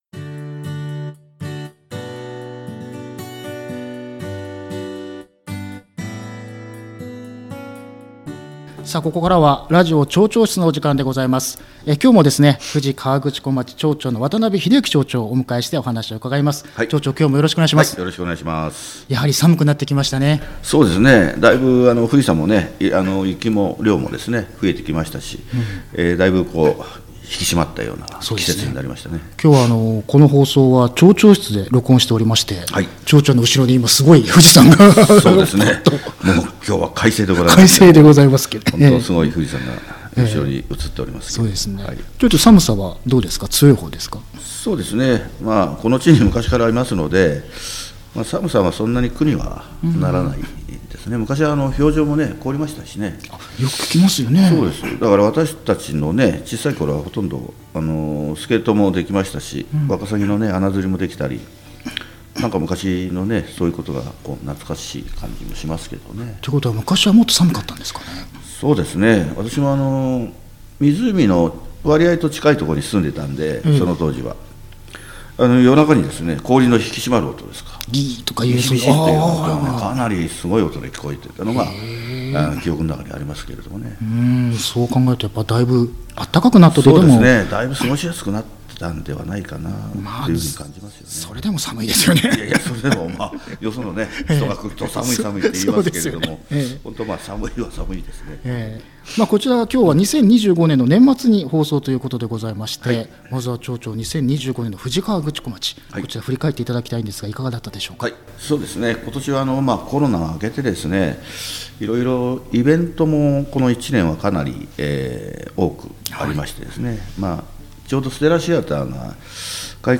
12月29日に放送したラジオ町長室（第4回）の放送アーカイブです。
今回も富士河口湖町の渡辺英之町長をゲストにお迎えし、様々なことをお伺いしました。